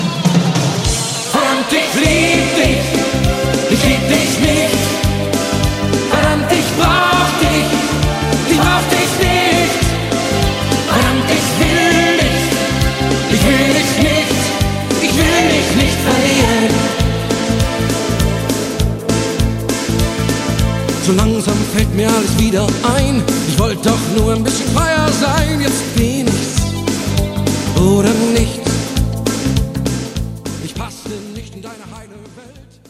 Gattung: Morderner Einzeltitel mit Gesang ad lib.
Besetzung: Blasorchester